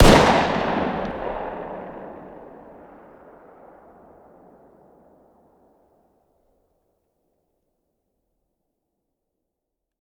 fire-dist-10x25-pist..>2024-09-10 22:10 504K